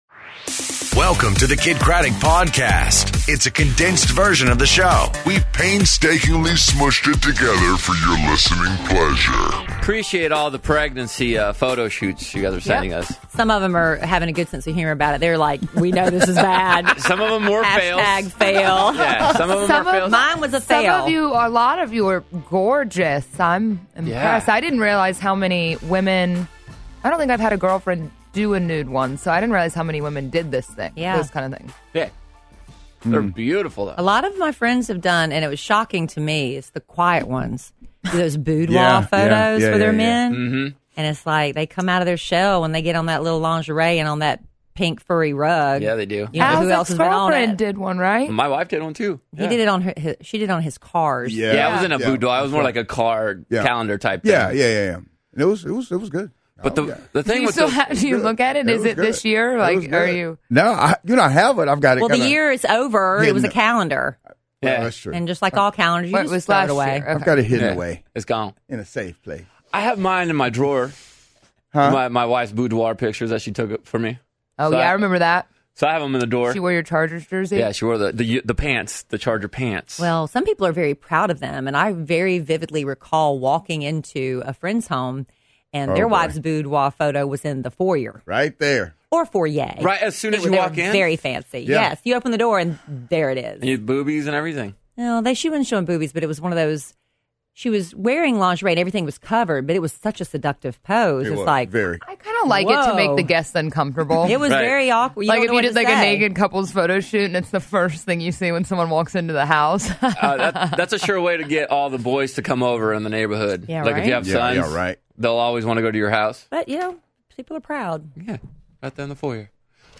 Jorge Blanco In Studio, Love Letters To Kellie, And Whatever Wednesday